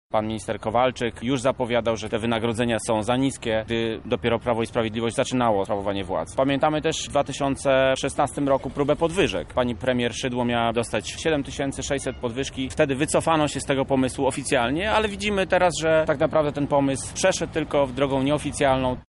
Bartosz Margul, radny Rady Miasta Lublin z ramienia Platformy Obywatelskiej mówi o rządowym planie podwyżek: